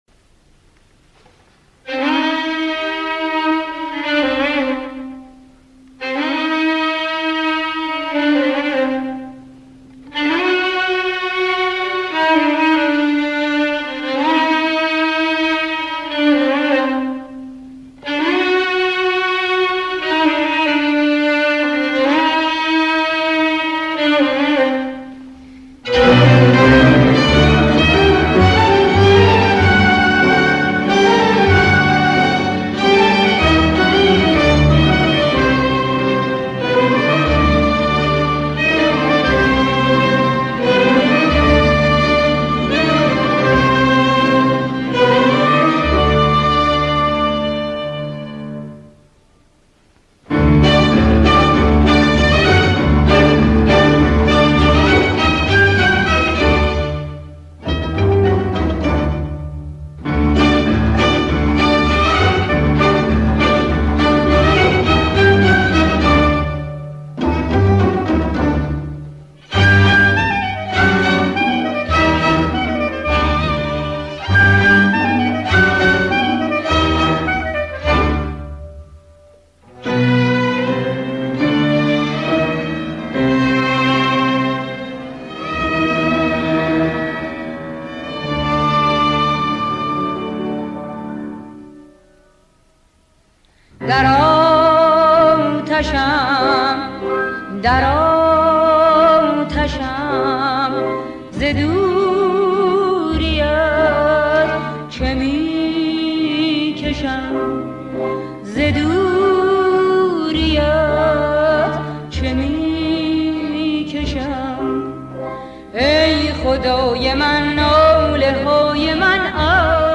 در مایه: اصفهان